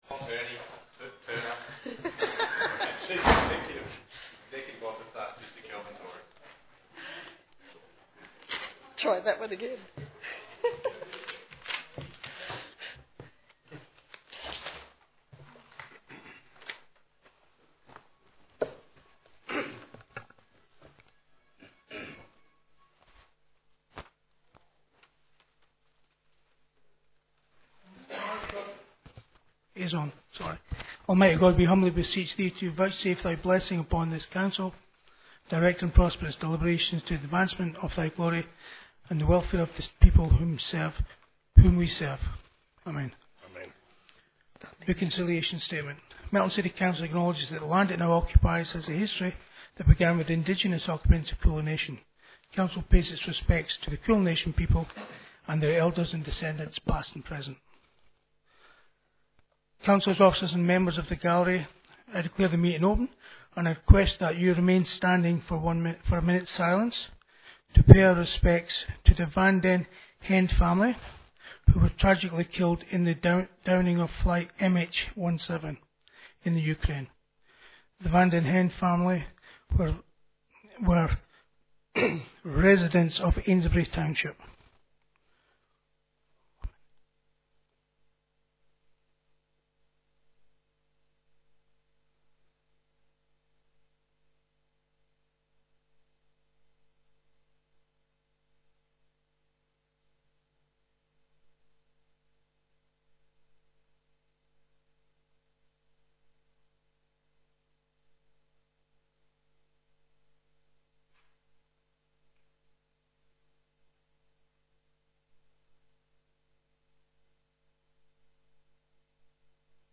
22 July 2014 - Ordinary Council Meeting